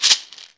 Shaker (4).wav